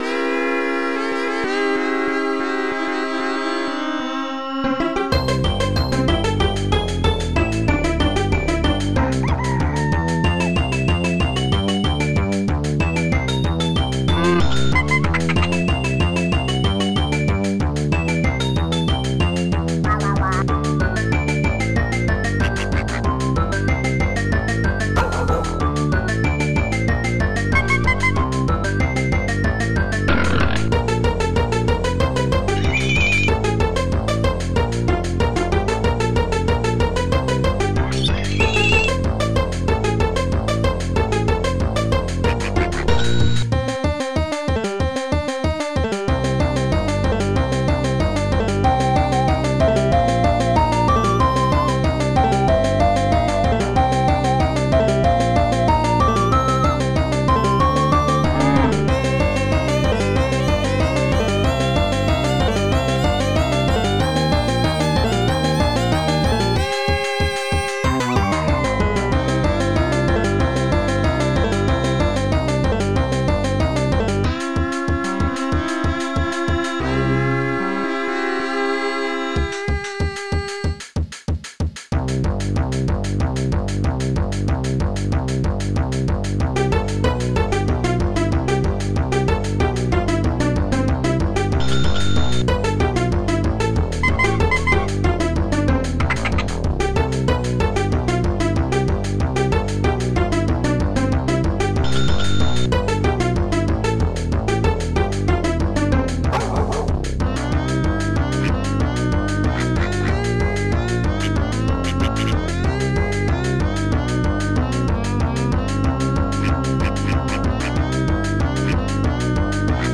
PingBells
BassDrum5
Cymbal1
Honky-Tonk
FunBass
PanFlute
cock crowing
Cowbell
bike bell
bulb horn
Dogbark
piggy grunt
cow
sheep
duck